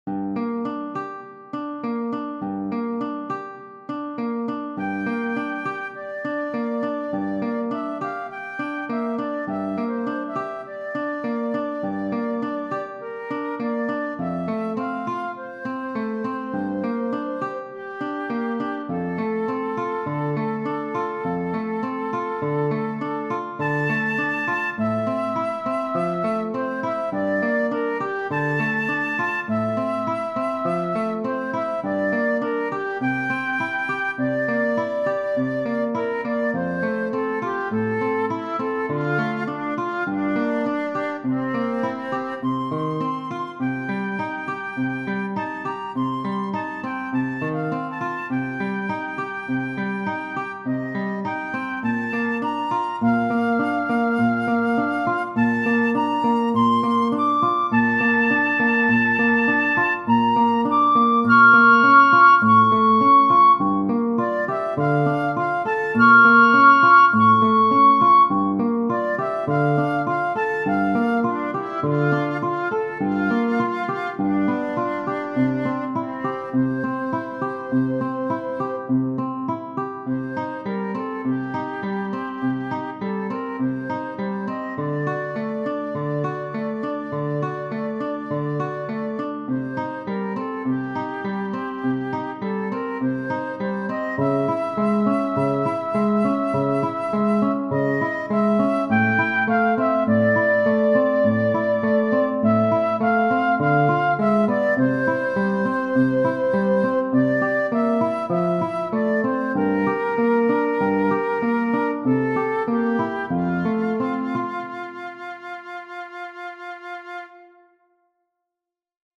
Debussy, C. Genere: Moderne Questo brano, "Reverie", pur essendo ancora legato a certe reminiscenze romantiche, si stacca dalle opere giovanili di Debussy, per una migliore tessitura musicale e per una fluida cantabilità della sua melodia.